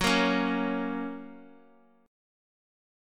Gb Chord
Listen to Gb strummed